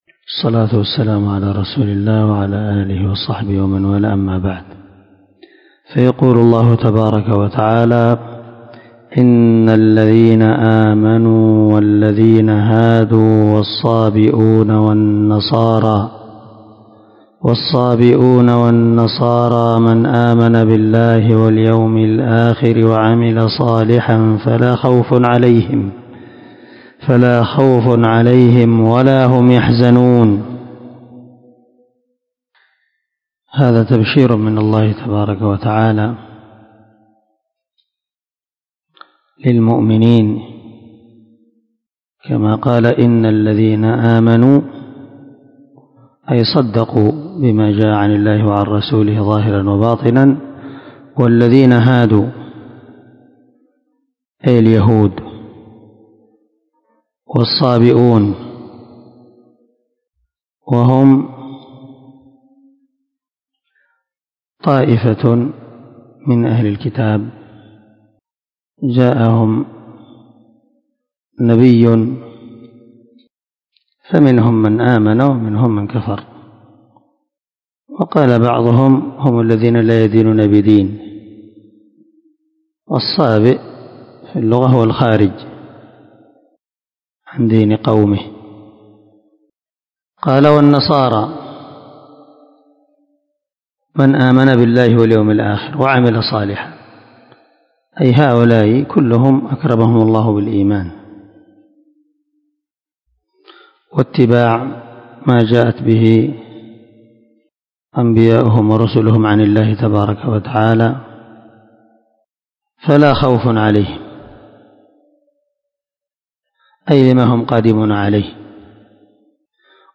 376الدرس 42تفسير آية ( 69 – 71 ) من سورة المائدة من تفسير القران الكريم مع قراءة لتفسير السعدي